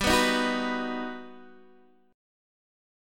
G+M7 chord